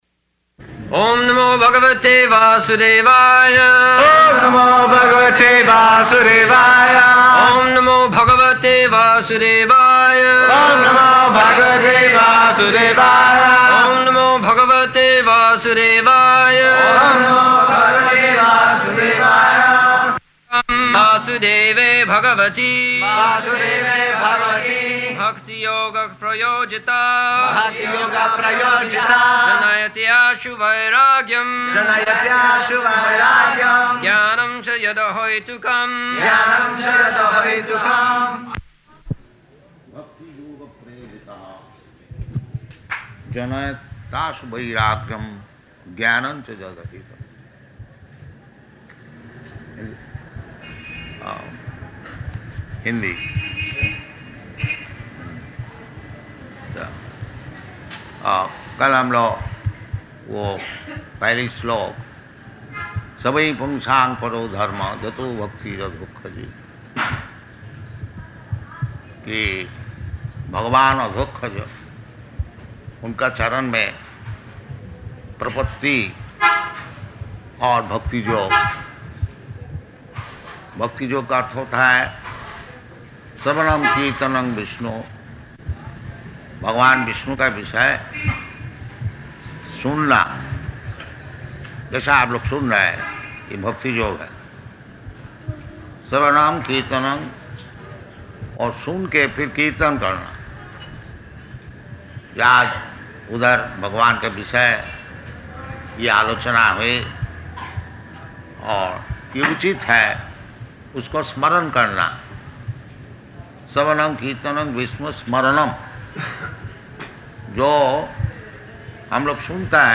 March 22nd 1975 Location: Calcutta Audio file
[devotees repeat]